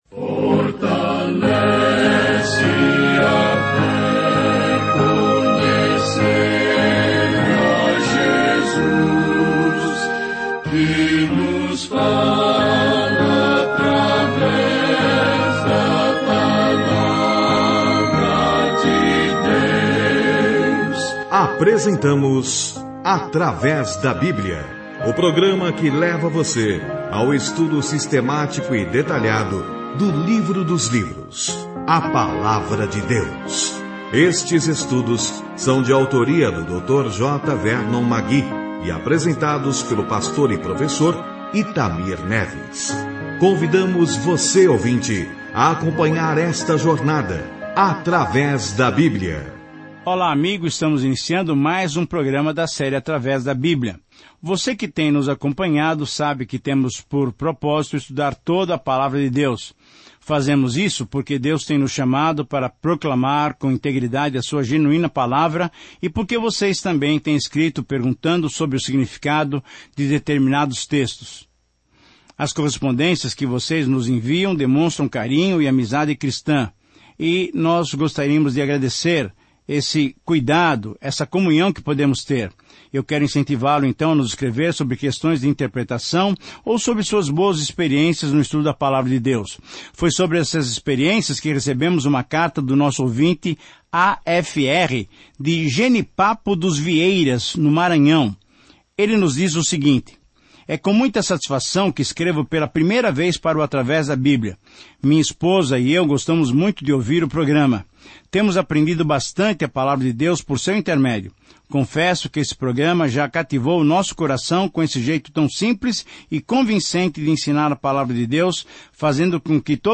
As Escrituras Juízes 10:1-18 Juízes 11:1-40 Juízes 12:1-15 Dia 7 Começar esse Plano Dia 9 Sobre este Plano Juízes registra as vidas às vezes distorcidas e de cabeça para baixo de pessoas que estão se adaptando às suas novas vidas em Israel. Viaje diariamente pelos Juízes enquanto ouve o estudo em áudio e lê versículos selecionados da palavra de Deus.